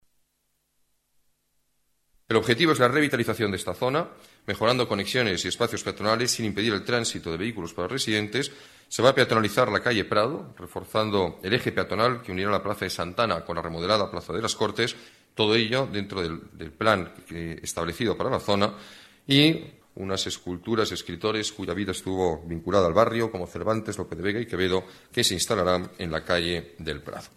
Nueva ventana:Declaraciones del alcalde de la Ciudad de Madrid, Alberto Ruiz-Gallardón: Remodelación Huertas